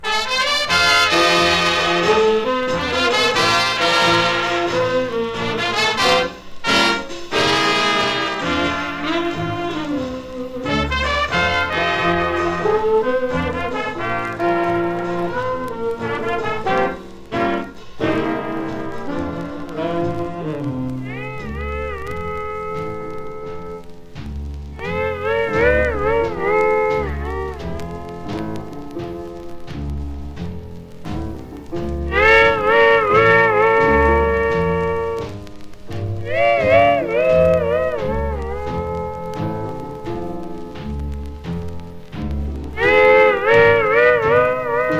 Jazz, Big Band, Swing　USA　12inchレコード　33rpm　Mono